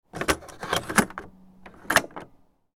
Cassette-tape-insertion-sound-effect.mp3